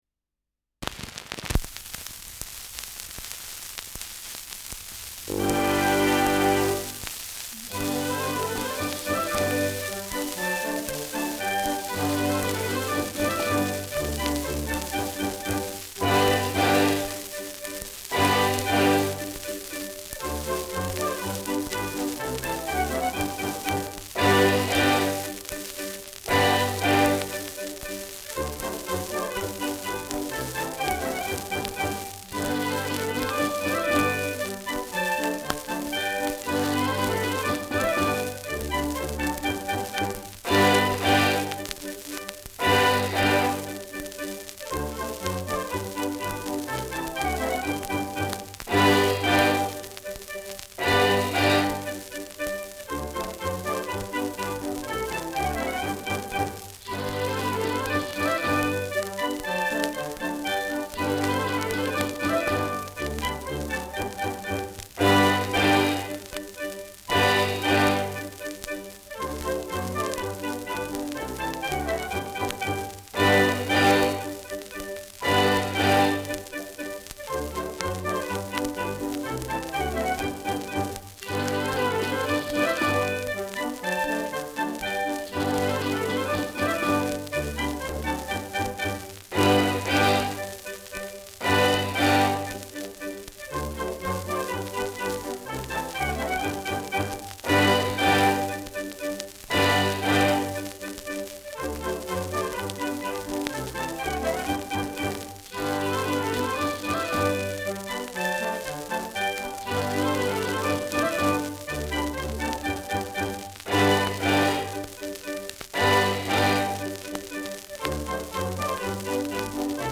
Schellackplatte
Stärkeres Grundrauschen : Vereinzelt leichtes Knacken
[unbekanntes Ensemble] (Interpretation)